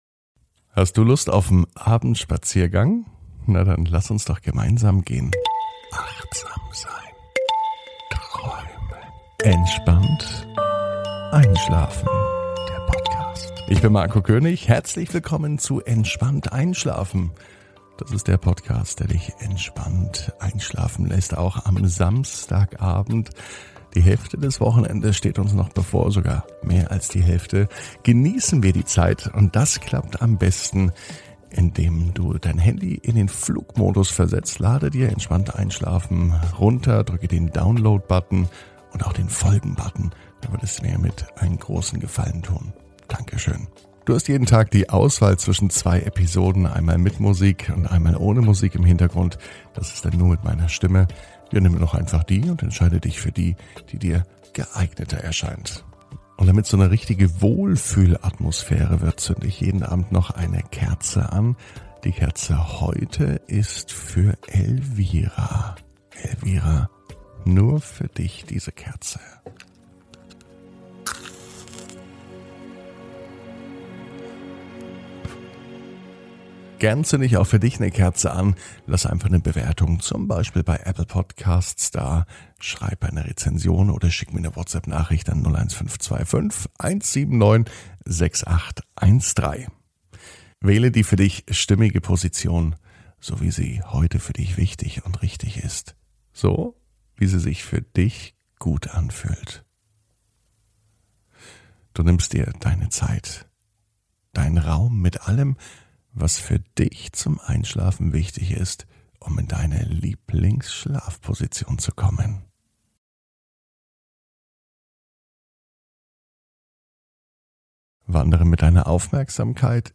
(ohne Musik) Entspannt einschlafen am Samstag, 12.06.21 ~ Entspannt einschlafen - Meditation & Achtsamkeit für die Nacht Podcast